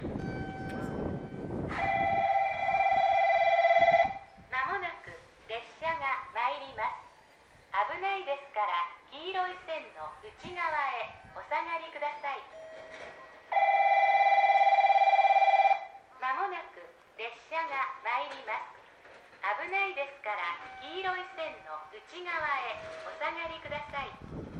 この駅では接近放送が設置されています。
２番のりば鹿児島本線
接近放送普通　鹿児島中央行き接近放送です。